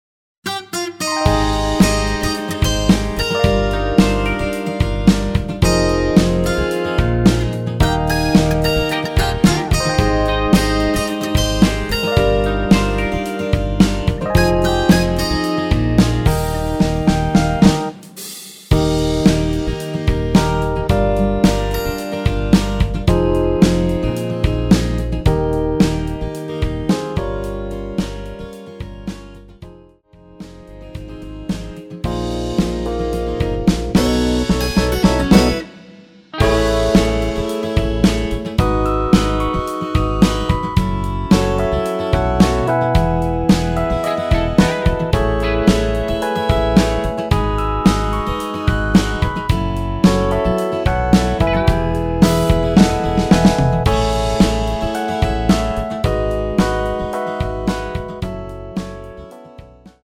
여성분이 부르실수 있는 키로 제작 하였습니다.
Eb
앞부분30초, 뒷부분30초씩 편집해서 올려 드리고 있습니다.
중간에 음이 끈어지고 다시 나오는 이유는